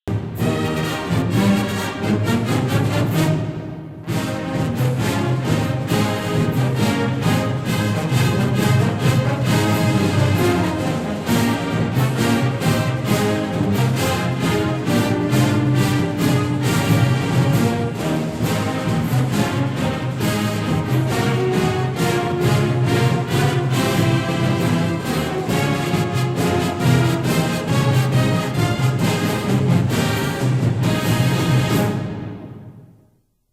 IN fight song